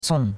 cong4.mp3